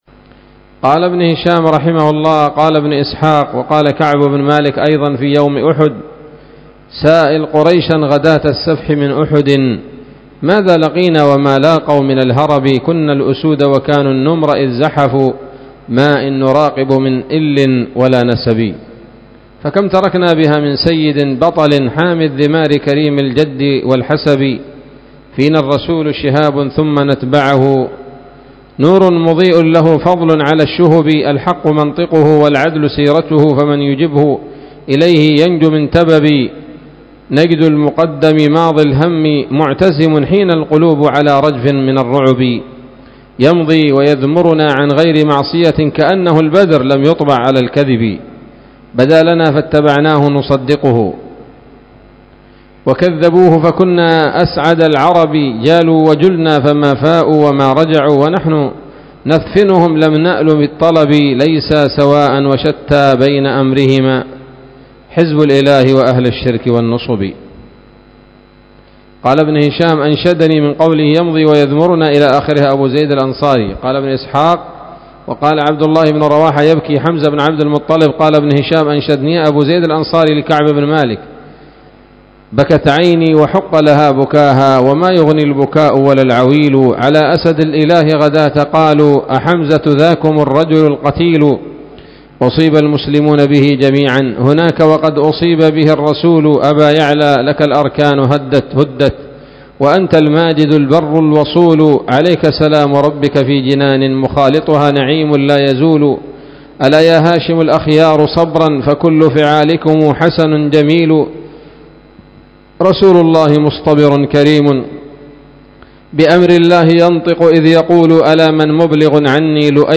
الدرس الثاني والثمانون بعد المائة من التعليق على كتاب السيرة النبوية لابن هشام